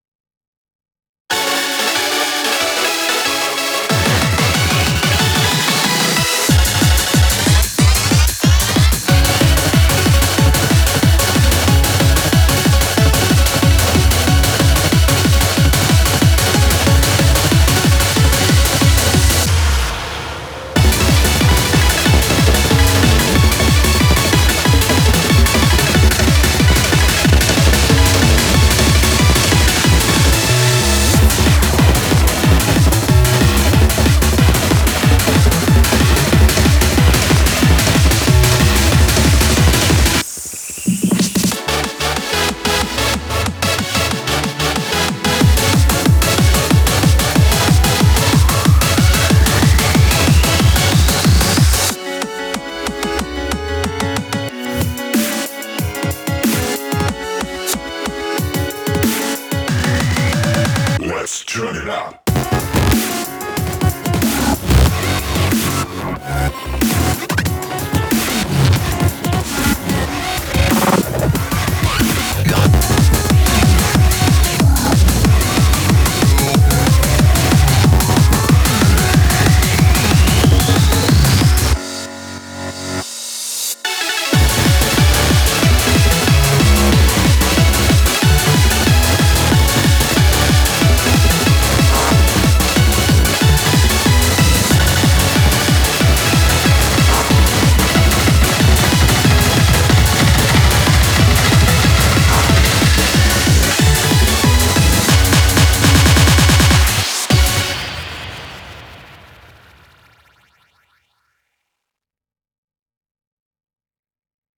BPM185
Audio QualityLine Out